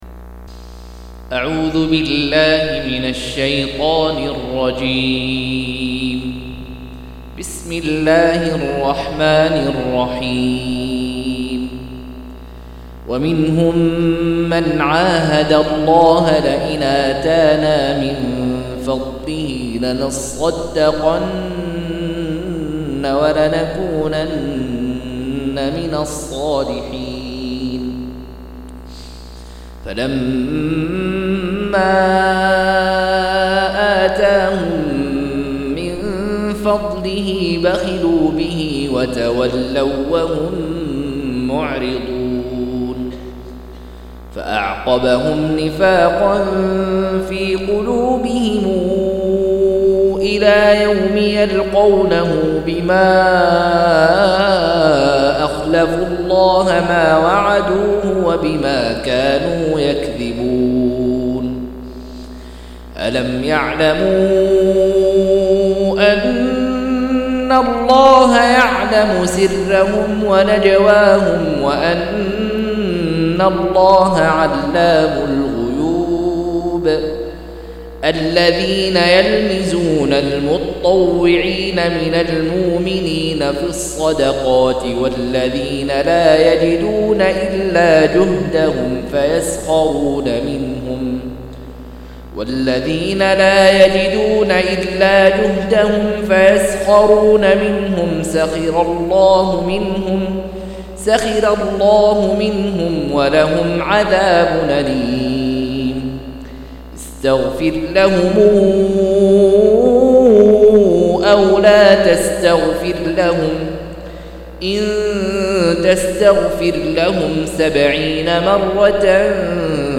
189- عمدة التفسير عن الحافظ ابن كثير رحمه الله للعلامة أحمد شاكر رحمه الله – قراءة وتعليق –